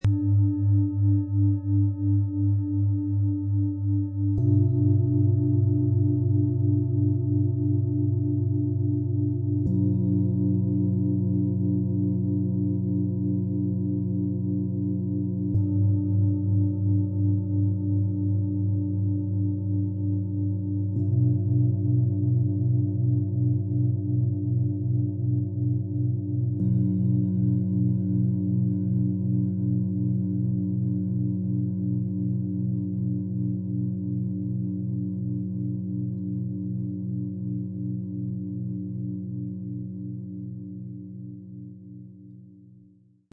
Komme an, finde Halt und öffne Deinen Horizont - Set aus 3 Planetenschalen, Ø 18,1 -23,5 cm, 3,22 kg
Geborgenheit, Stabilität und Weitblick entfalten sich in diesem energetisch fein abgestimmten Klangfeld.
Erfahrene Meister formen jede Schale mit Hingabe und überliefertem Wissen - für tiefe Resonanz und harmonische Schwingung.
Mit unserem Sound-Player - Jetzt reinhören haben Sie die Möglichkeit, den authentischen Ton dieser speziellen Schalen des Sets zu hören.
Tiefster Ton: Saturn
Mittlerer Ton: Mond
Höchster Ton: Jupiter